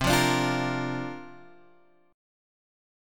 C9sus4 chord {8 8 x 7 6 6} chord